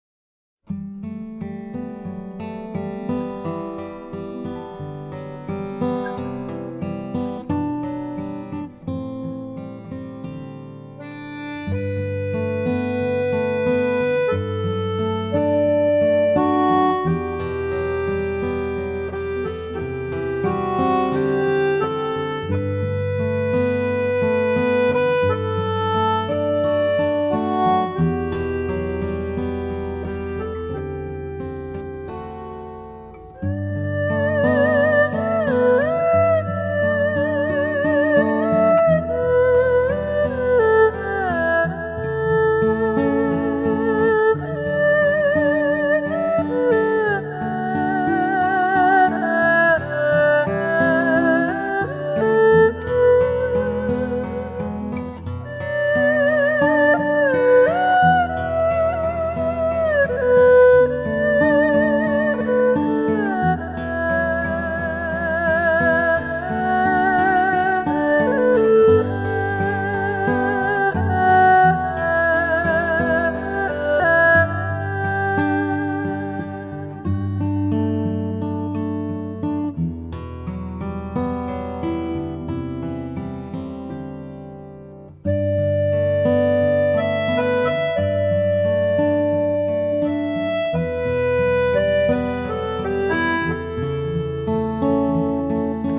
★ 在傳統樂音中注入古老而優美的全新生命力，帶來最令人愉悅撫慰的心靈詩篇！